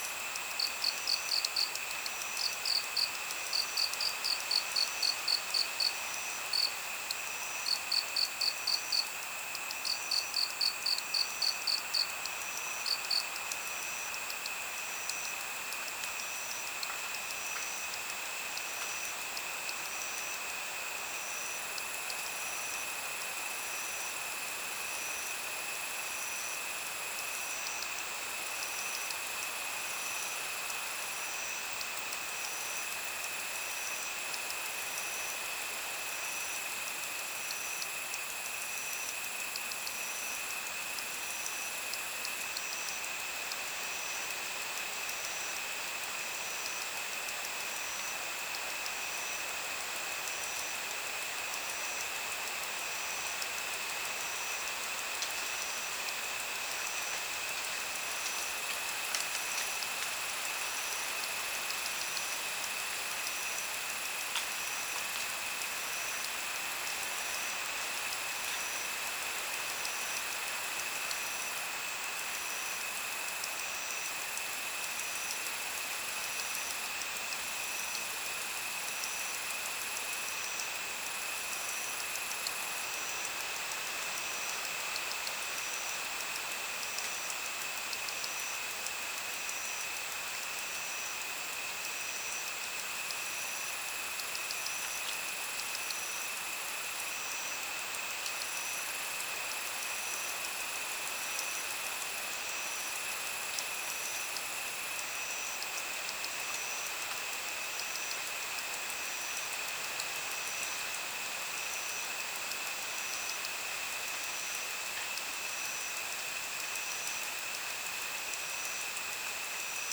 治愈 环绕音 ASMR